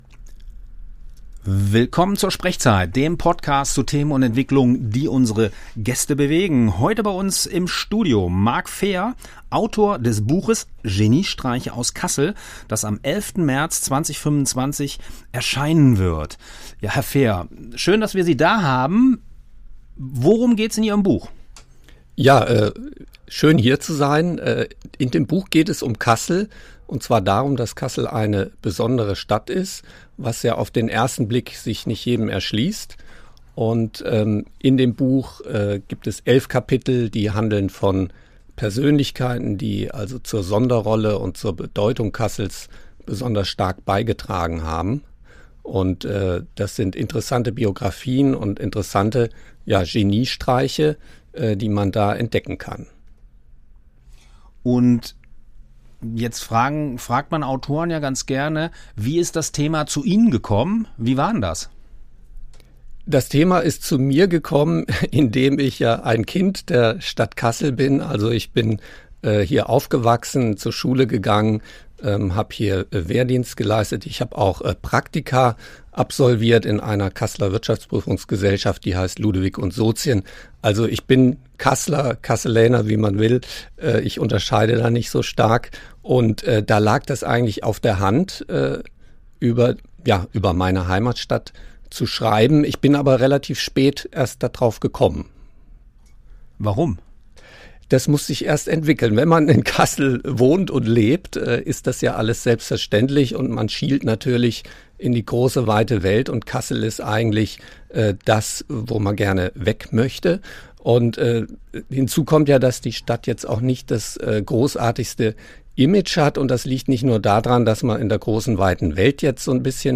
Dieser SprechZeit-Podcast wurde am 24.2.2025 beim Freien Radio Kassel aufgezeichnet.